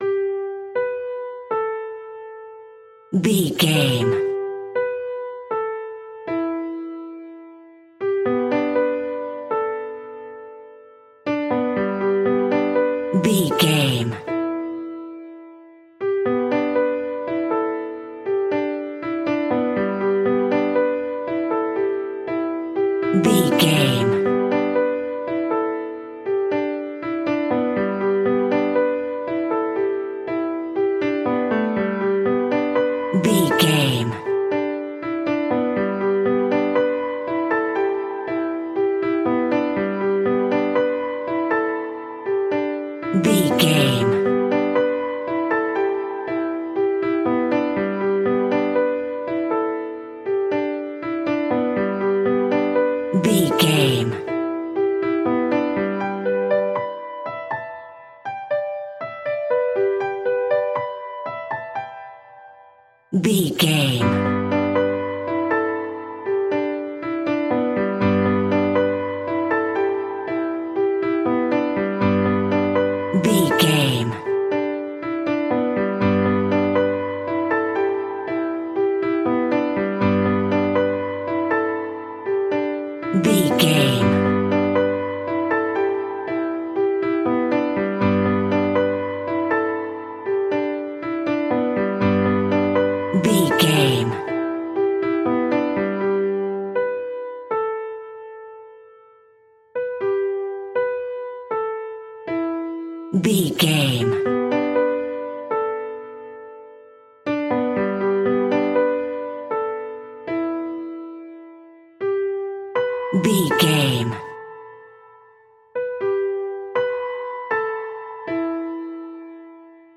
Piano Children Playing Music.
Uplifting
Ionian/Major
childlike
cute
happy
kids piano